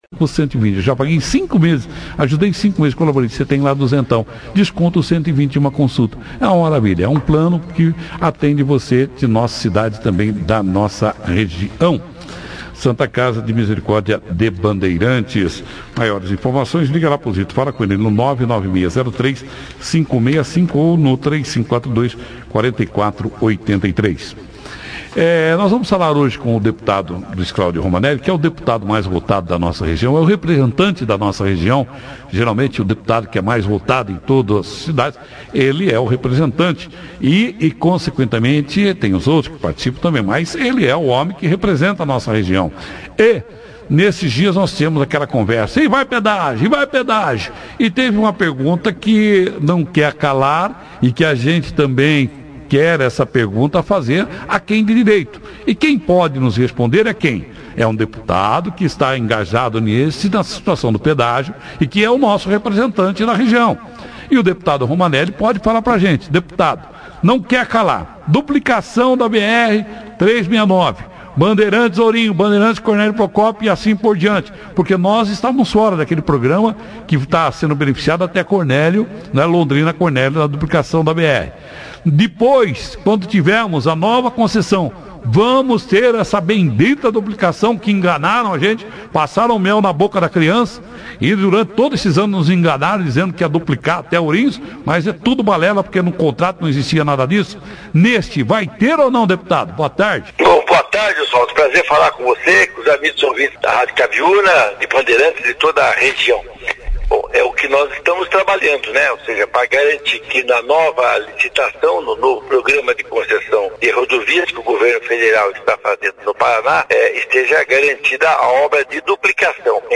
Deputado Romaneli, falou ao vivo no Operação Cidade